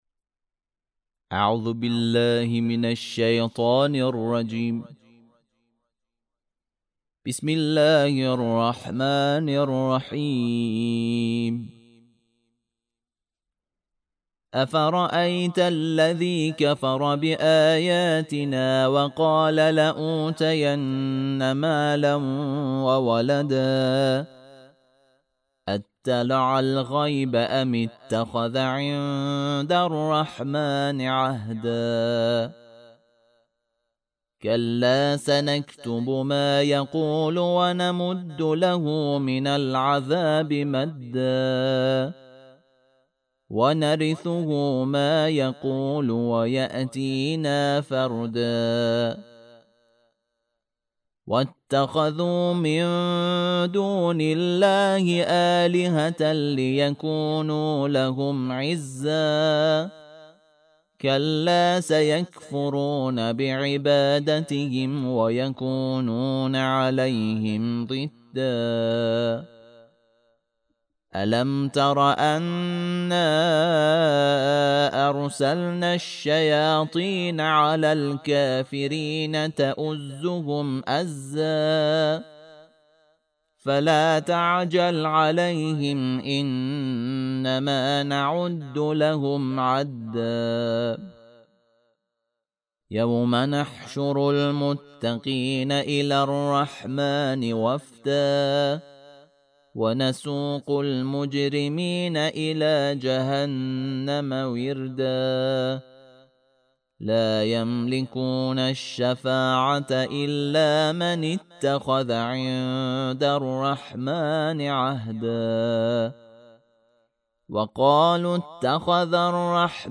ترتیل صفحه ۳۱۱ سوره مبارکه مریم(جزء شانزدهم)